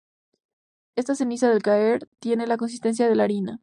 Pronounced as (IPA) /aˈɾina/